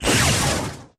Подборка включает разные варианты звучания, от глухих разрядов до шипящих импульсов.
Звук пролетевшей с неба шаровой молнии